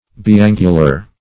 Search Result for " biangular" : The Collaborative International Dictionary of English v.0.48: Biangular \Bi*an"gu*lar\, a. [Pref. bi- + angular.] Having two angles or corners.